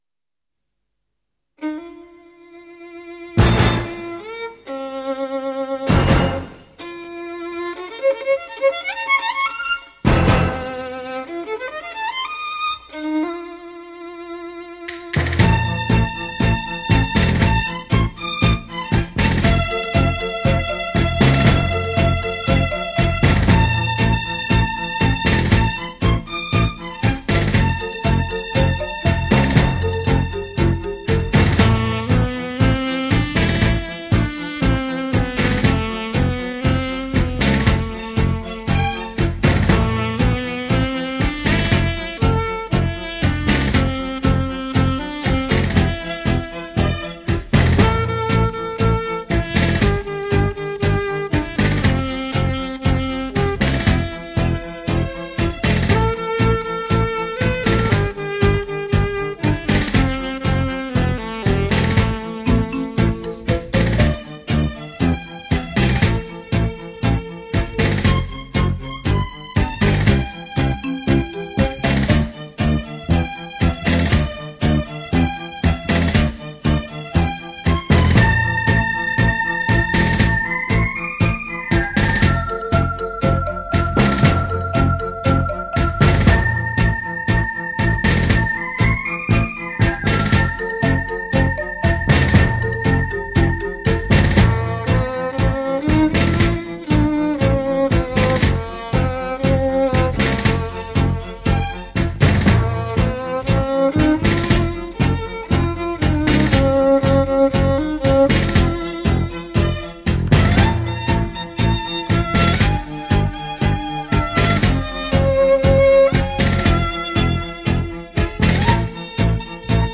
以传统交谊舞的舞步节奏为主
以鲜明的节奏、优雅的曲风
探戈  源于阿根廷，2/4拍子。舞曲节奏鲜明，每小节两拍，都是重音。